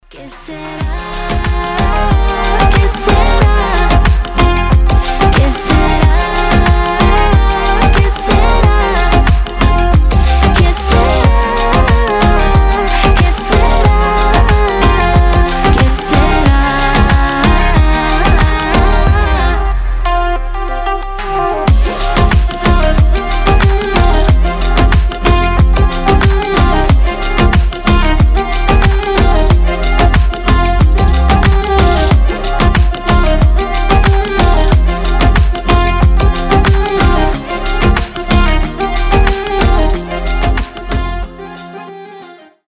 • Качество: 320, Stereo
мелодичные
дуэт
Dance Pop
восточные